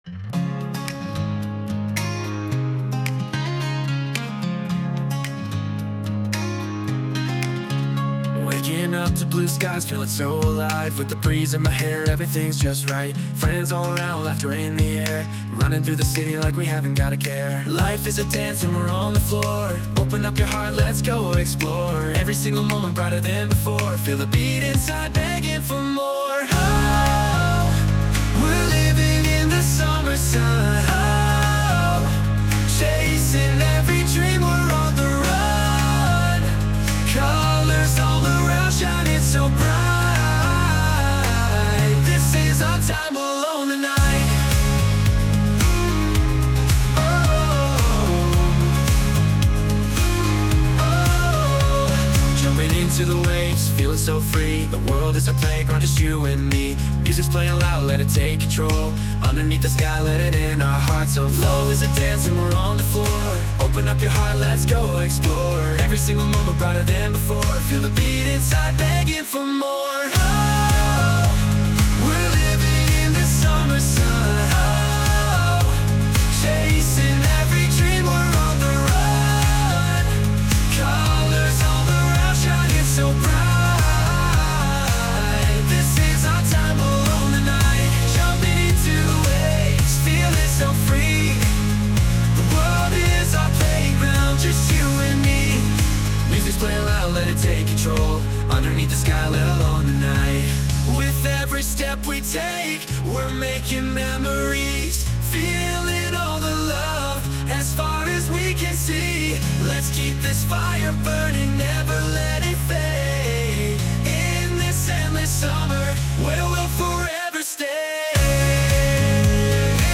洋楽男性ボーカル著作権フリーBGM ボーカル
著作権フリーのオリジナルBGMです。
男性ボーカル（英語・洋楽）曲です。
夏らしい元気な明るい曲をイメージして制作しました✨